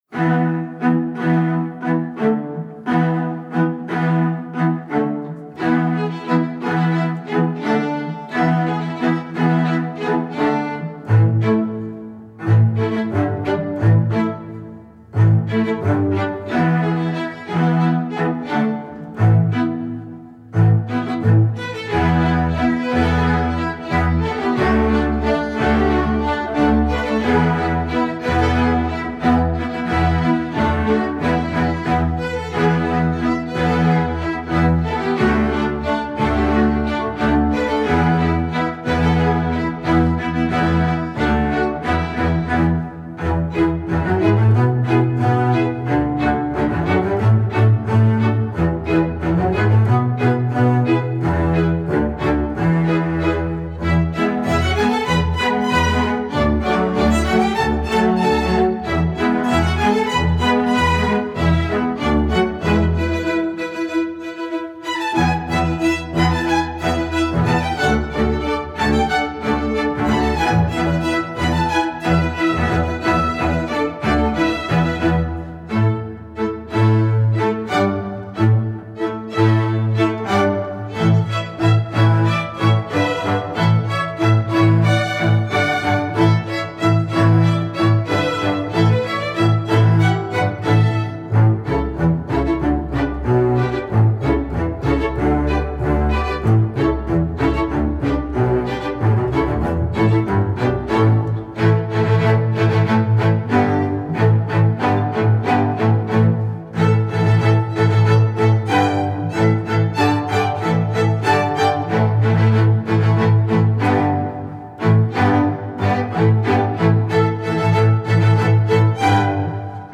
Voicing: String Orc